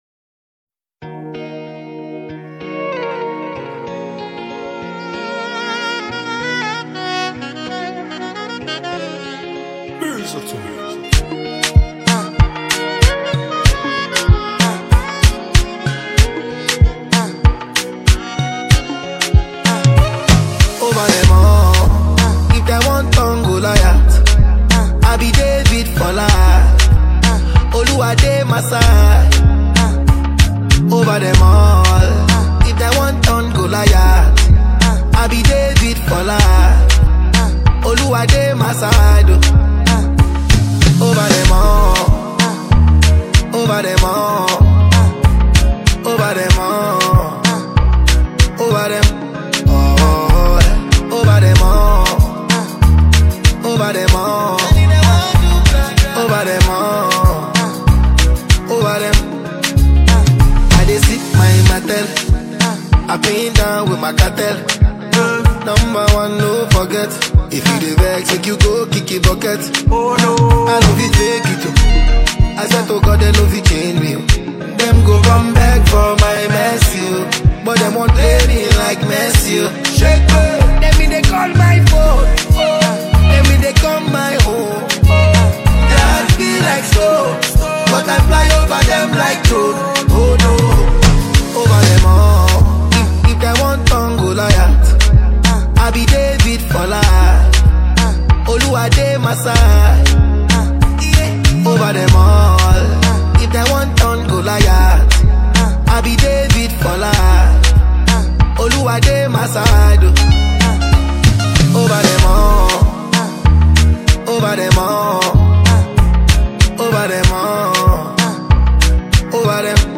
Popular afro singer